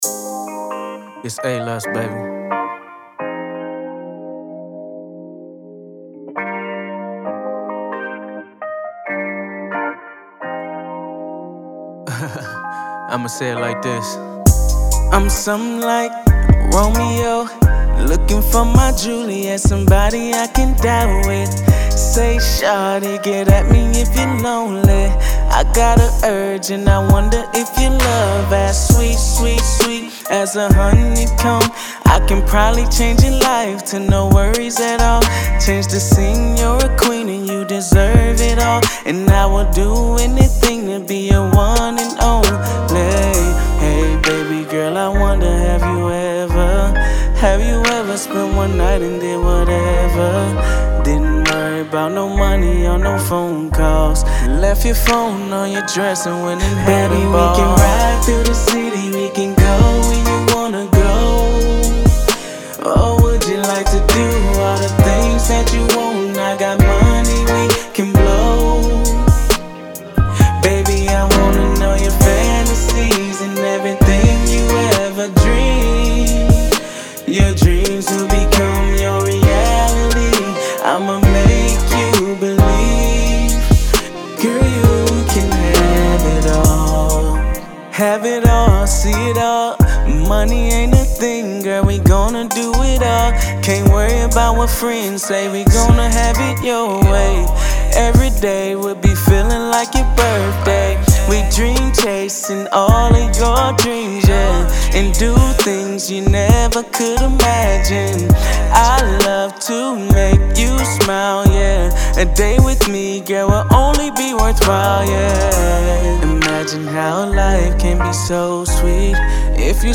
RnB
Good ol R&B